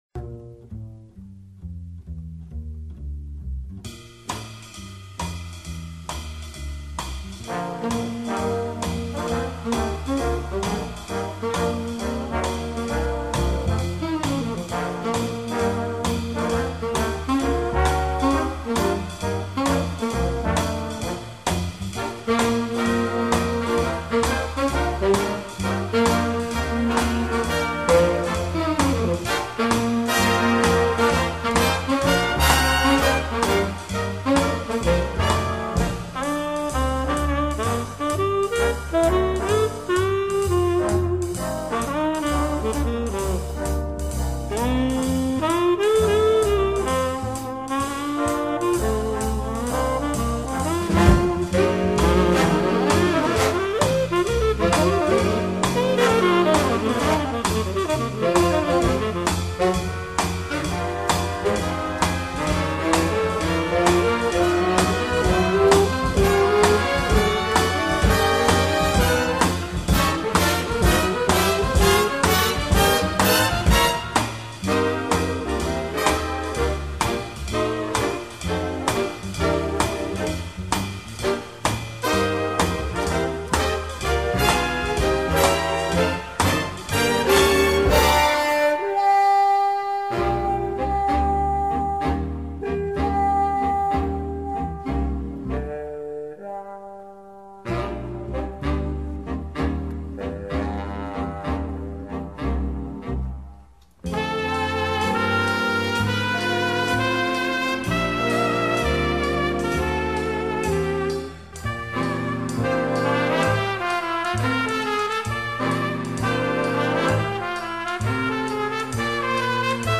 Big Band
• SWING (JAZZ)
• MAINSTREAM (JAZZ)
• Piano
• Tenor Sax
• Trombone
• Trumpet
• Bass
• Drums